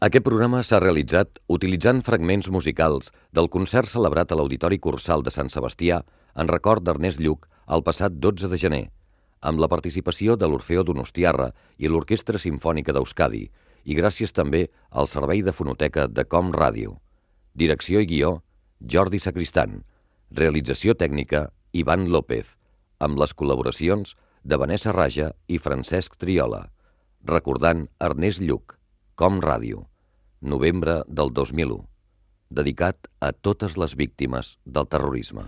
Programa especial emès amb motiu del primer aniversari de l'assassinat del polític Ernest Lluch per la banda terrorista ETA.
Crèdits finals del programa.
FM